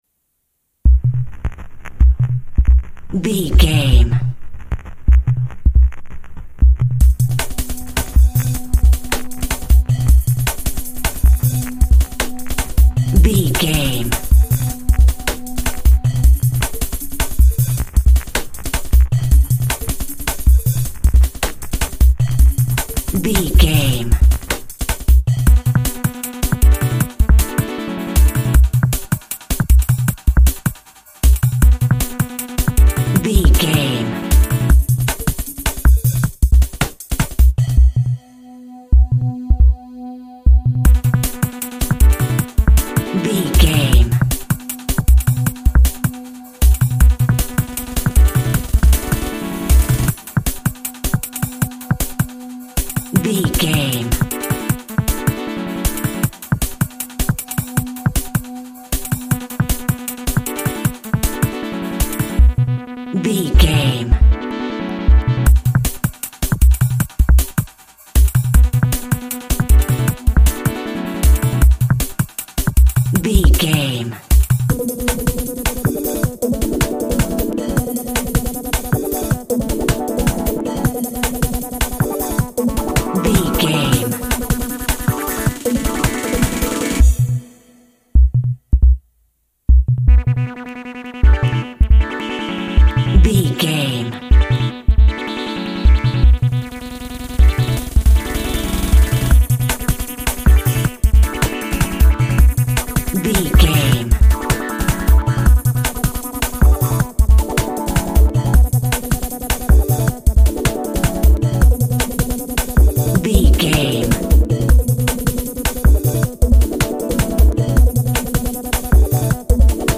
Aeolian/Minor
Fast
groovy
uplifting
driving
energetic
repetitive
house
electro
techno
trance
synth lead
synth bass
electronic drums
Synth Pads